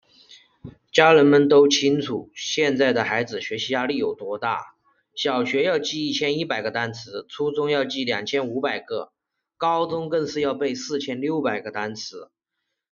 Voix de vente en direct haute énergie
Augmentez les conversions avec une voix IA persuasive et à forte rétention, adaptée aux ventes de commerce électronique en temps réel et aux démonstrations de produits.
Synthèse vocale
Ton persuasif
Notre IA imite la livraison rythmique et énergique des streamers les plus performants.